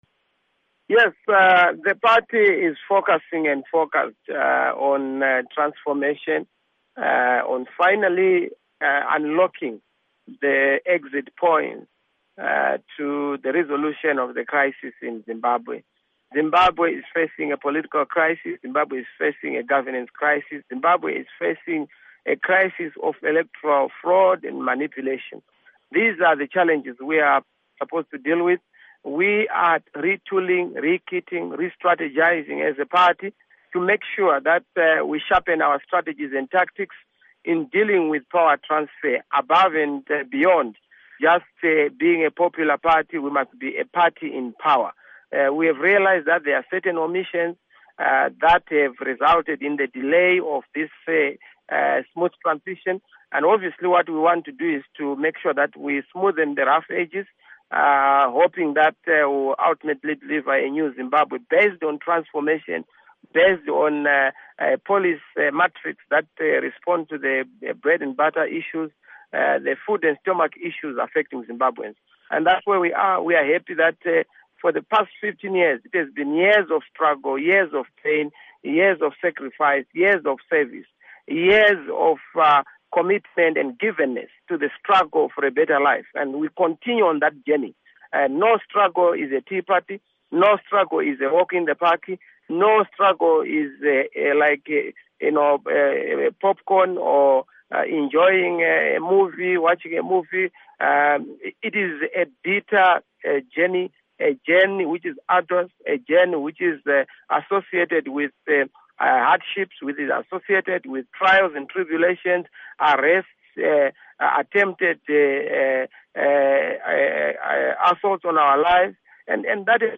Interview With MDC-T Organizing Secretary Nelson Chamisa